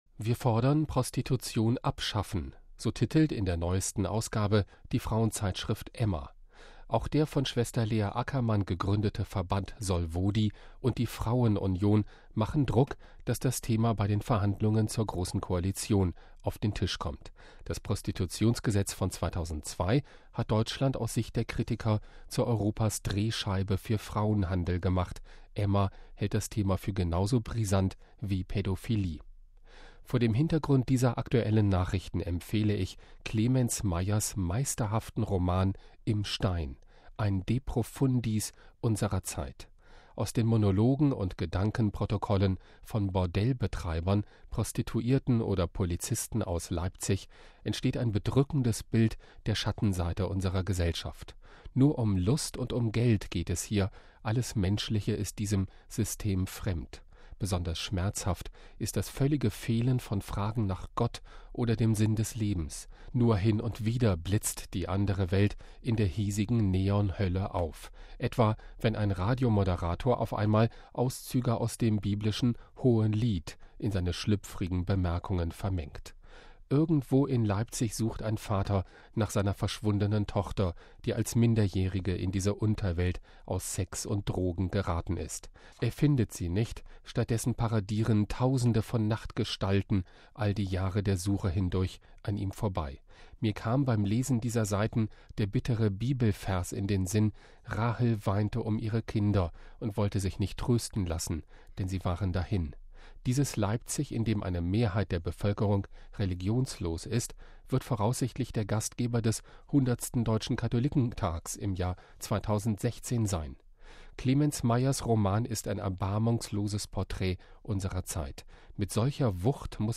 Eine Besprechung